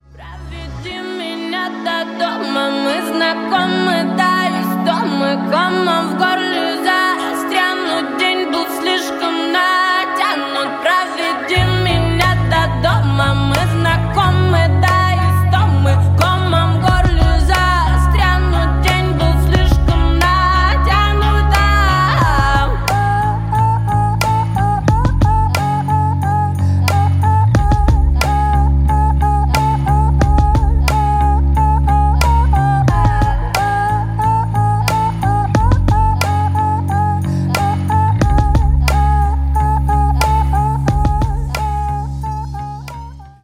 • Качество: 128, Stereo
поп
женский вокал
атмосферные
спокойные